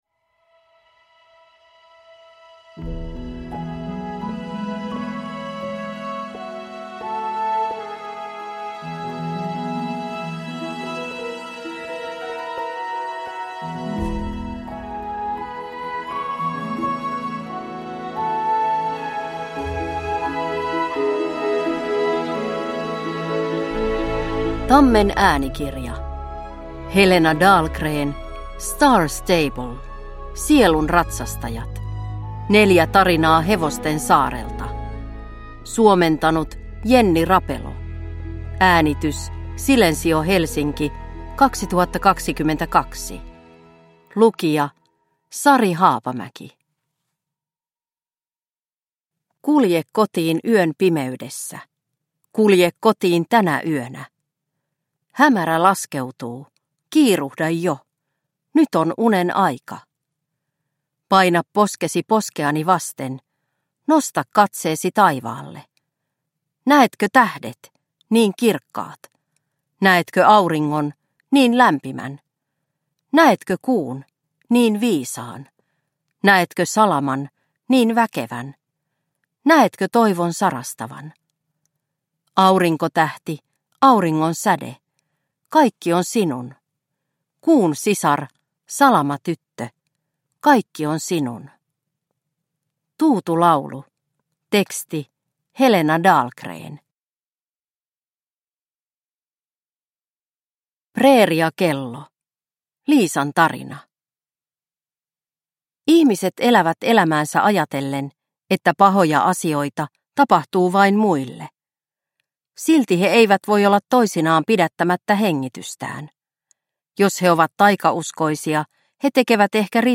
Star Stable. Sielunratsastajat. Neljä tarinaa hevosten saarelta – Ljudbok